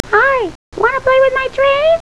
snd_26181_train?.wav